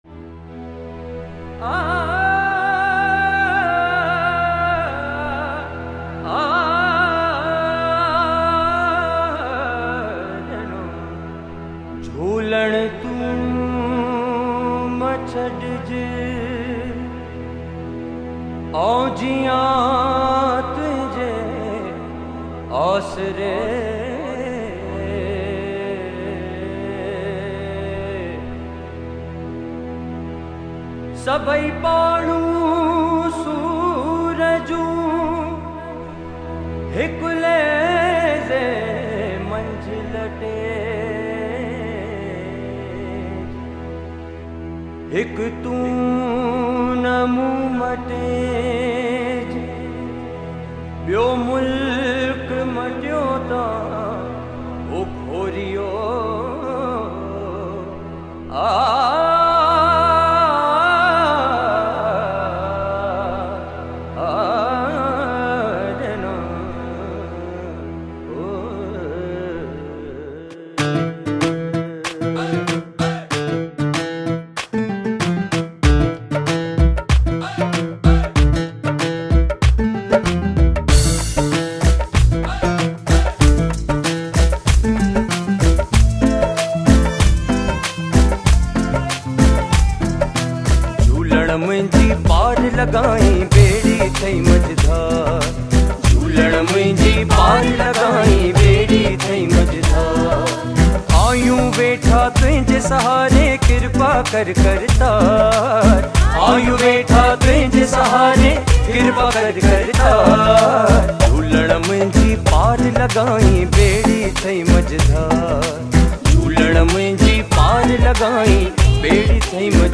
Sindhi Devotional songs